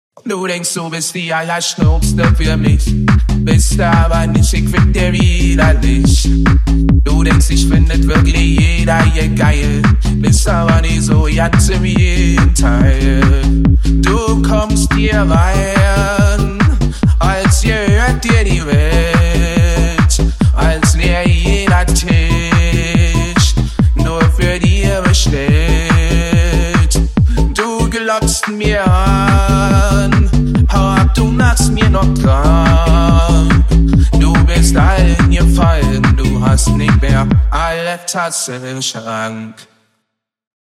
ElectronicMusic
hardtekk